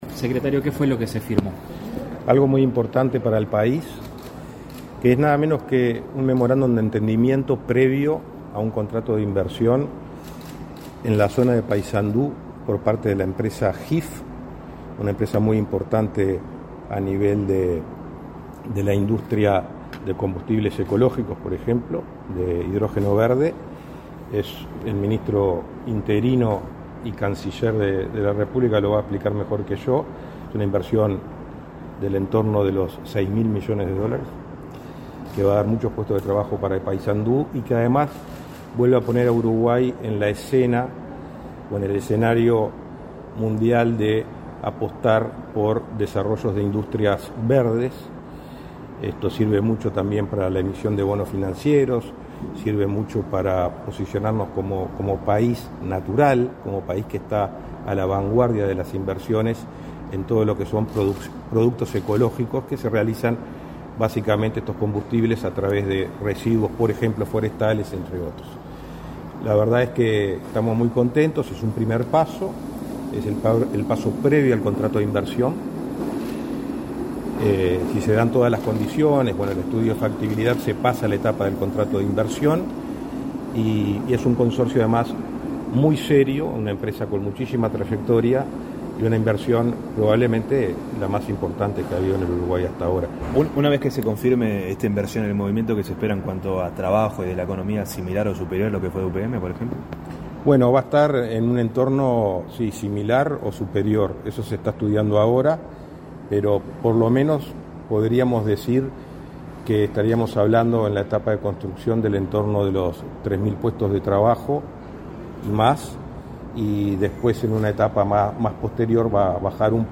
Declaraciones a la prensa de Rodrigo Ferrés y Omar Paganini
Tras el evento, el secretario de la Presidencia, Rodrigo Ferrés, y el ministro interino de Industria, Omar Paganini, efectuaron declaraciones.